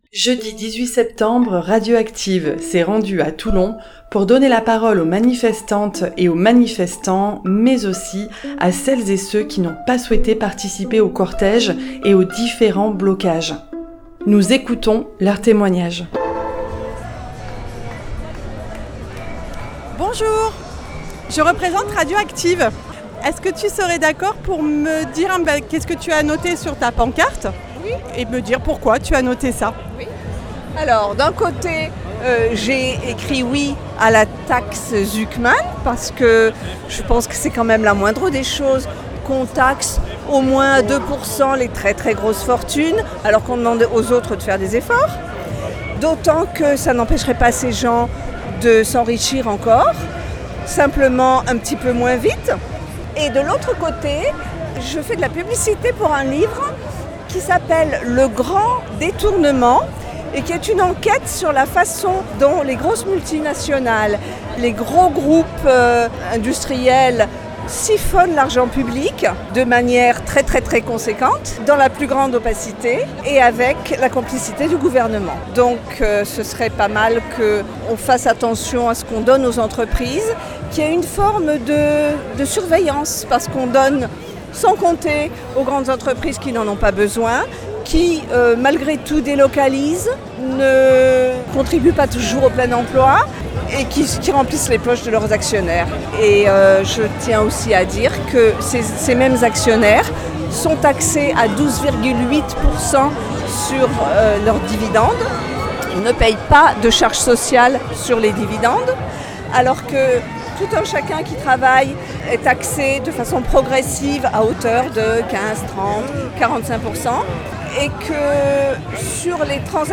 Interview 9 (18 septembre 2025)